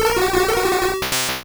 Cri de Soporifik dans Pokémon Rouge et Bleu.